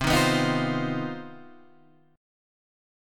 CmM11 chord